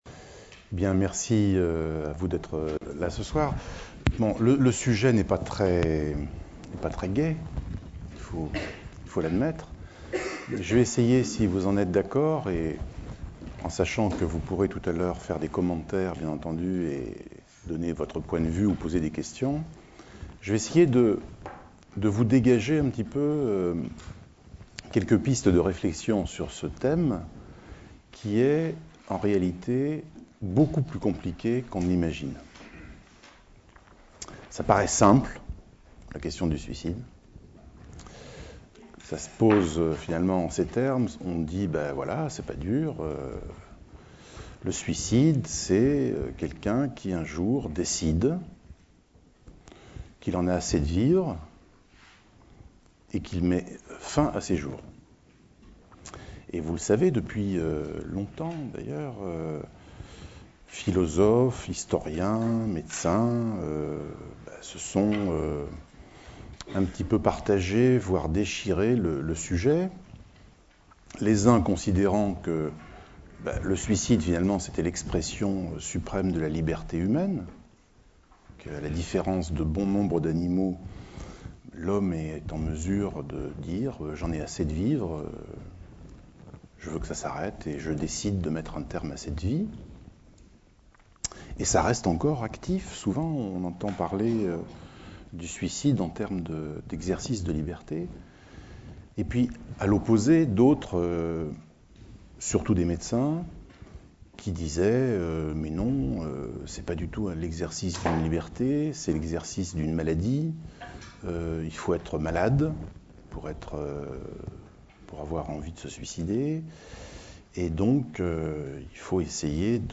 Conférence publique sur le suicide et ses raisons, revenant sur grand nombre d'idées reçues. Le mot suicide a été introduit par un prêtre au 18ème siècle pour contrebalancer un mot trop fort homicide. La mortalité en France a baissé de 40% sauf le suicide qui n'a baissé que de 20%. 3 suicidés sur 4 sont des hommes plutôt âgés en milieu rural qui utilisent des méthodes très violentes comme la pendaison et l'arme à feu.